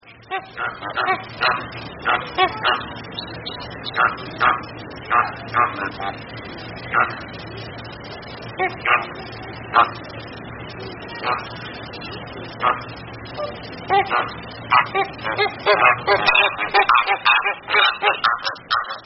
Fenicottero
(Phoenicopterus roseus )
fenicottero_rosa.mp3